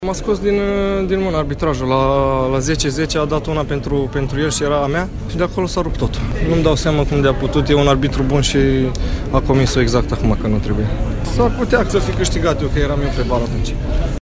declarație